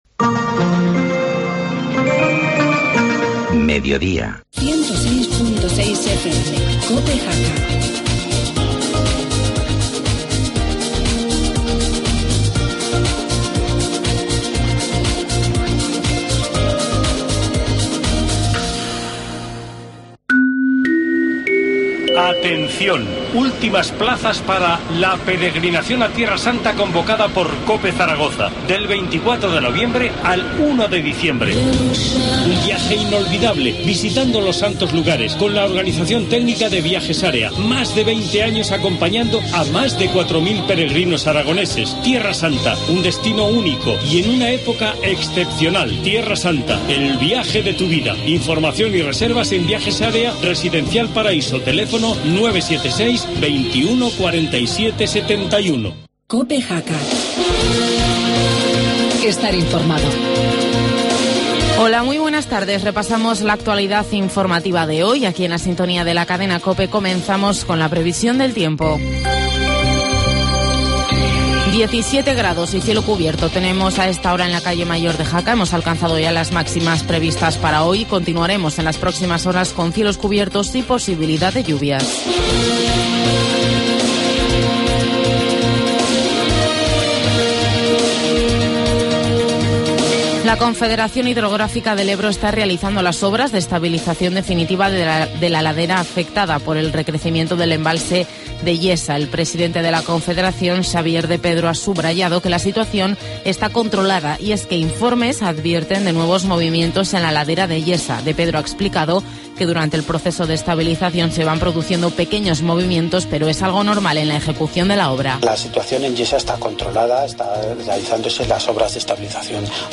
Informativo mediodía, martes 15 de octubre